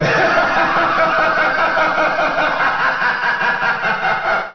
Voice clip from Super Smash Bros. Melee
Master_Hand_Laugh_(Melee).oga.mp3